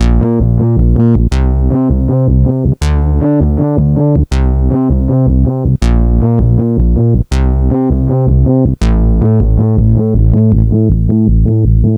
bassbridge.aiff